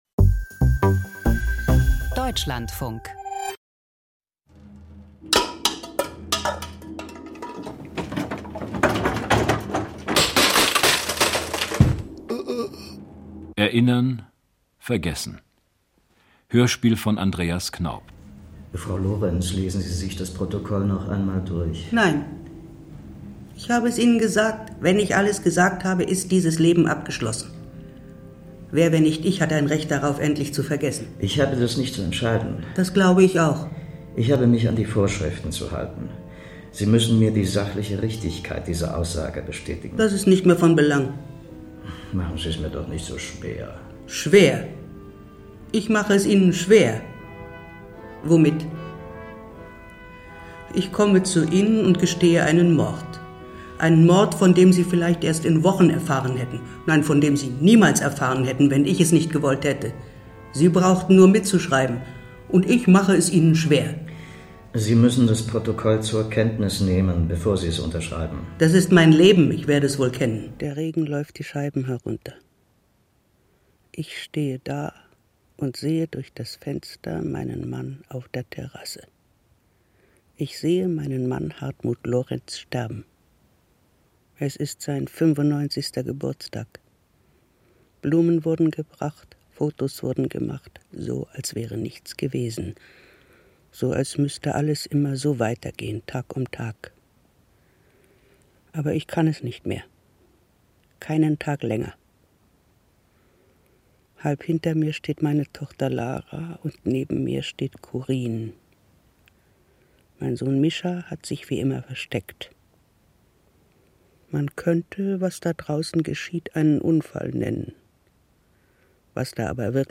Krimi-Hörspiel: Ein Mord, zwei Geständnisse - Erinnern – Vergessen